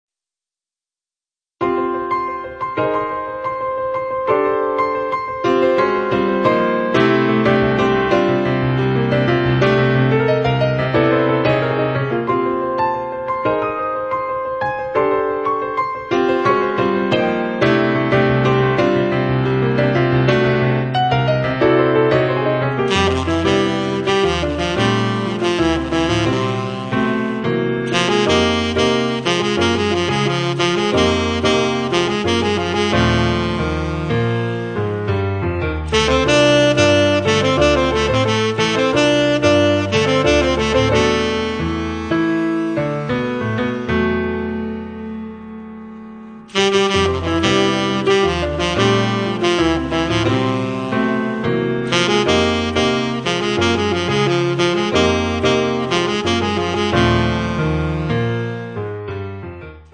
pianoforte, fender rhodes
sax tenore e soprano